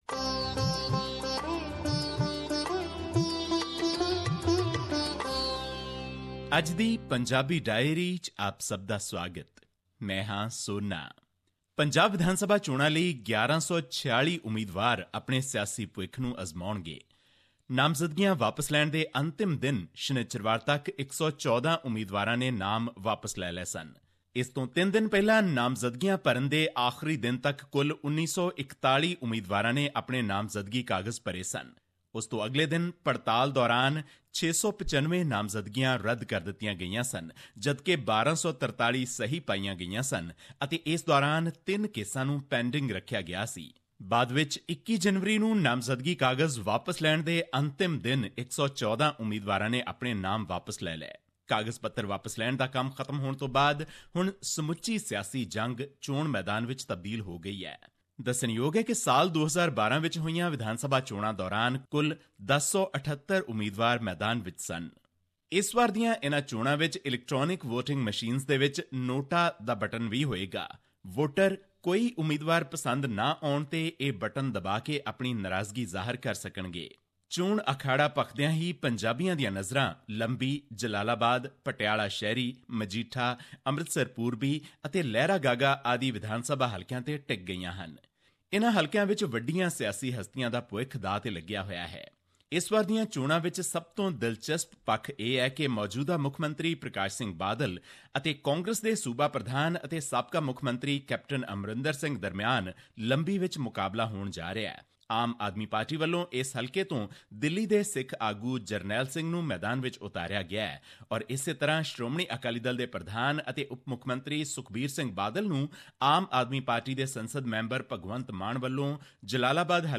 His report was presented on SBS Punjabi program on Monday, Jan 23, 2017, which touched upon issues of Punjabi and national significance in India. Here's the podcast in case you missed hearing it on the radio.